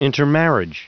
Prononciation du mot intermarriage en anglais (fichier audio)
intermarriage.wav